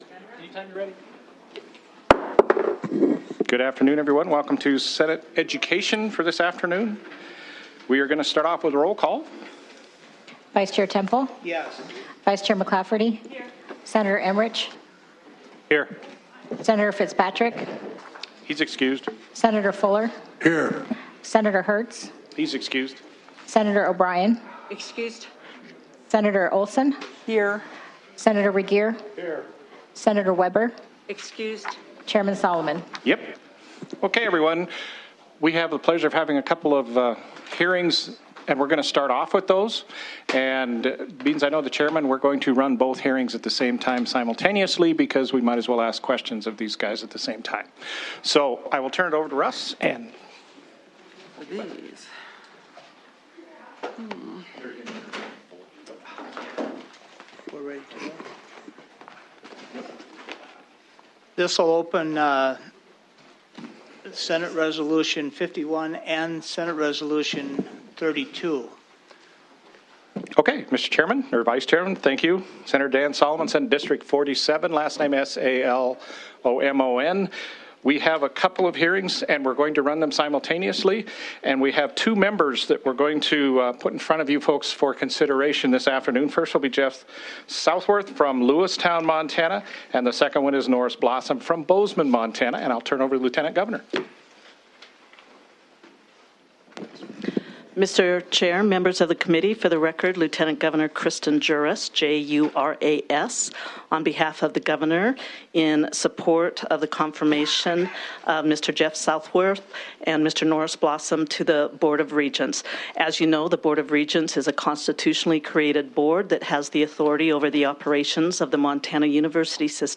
Senate Education and Cultural Resources